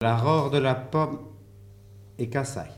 Localisation Notre-Dame-de-Monts
Locutions vernaculaires
Catégorie Locution